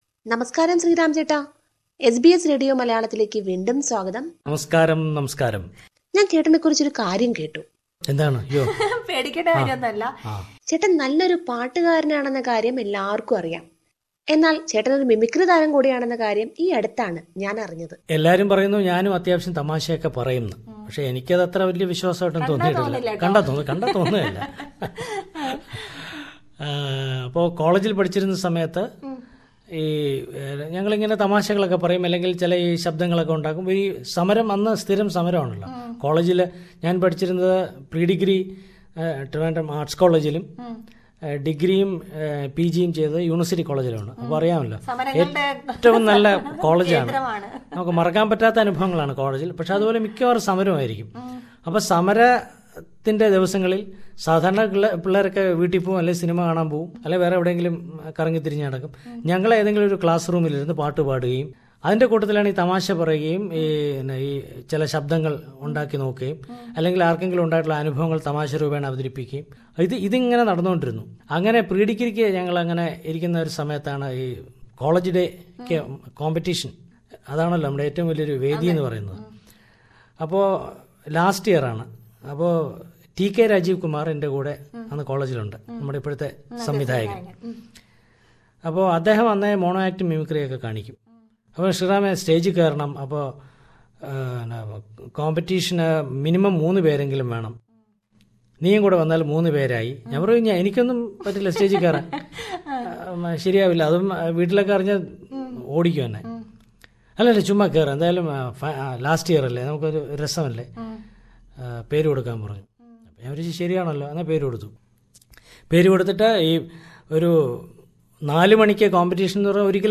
SBS Malayalam last week gave the interview